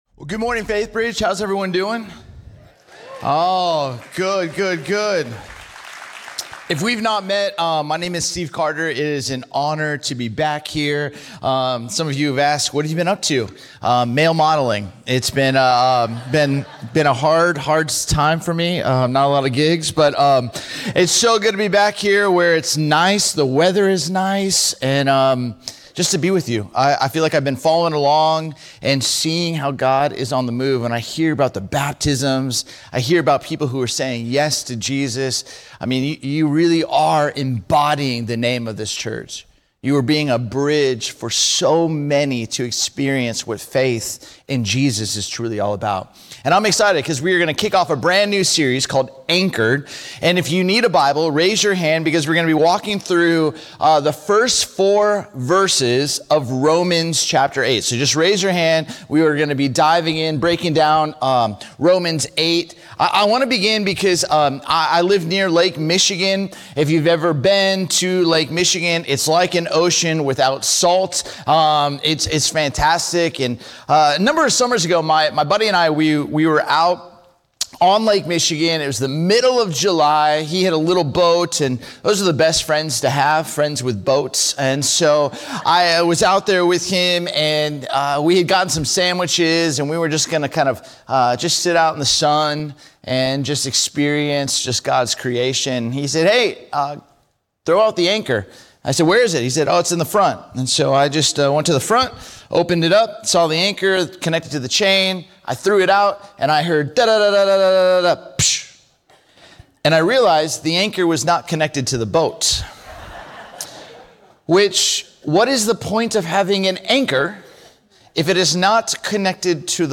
Faithbridge Sermons Anchored without Condemnation Apr 12 2026 | 00:35:52 1x 00:00 / 00:35:52 Subscribe Share Apple Podcasts Spotify Overcast RSS Feed Share Link Embed